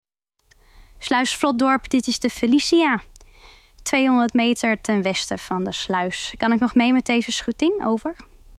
De Felicia roept je op.